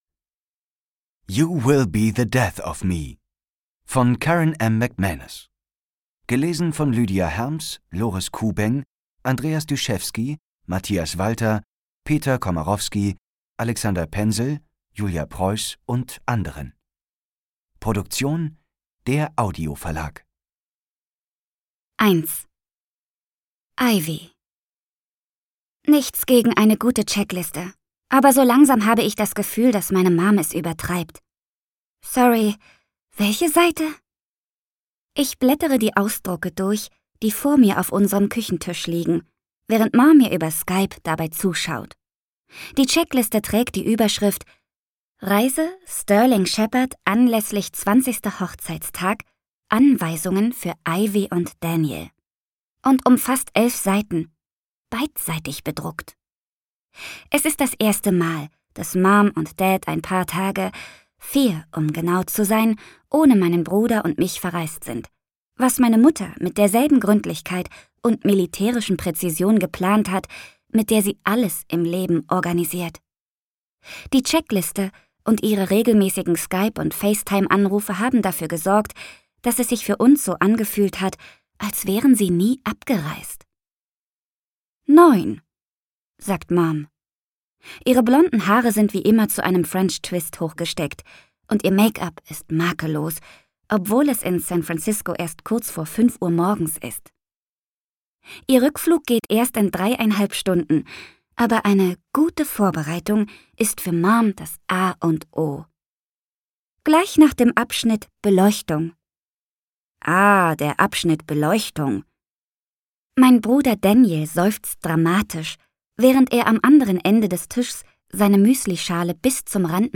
You Will Be the Death of Me Ungekürzte Lesung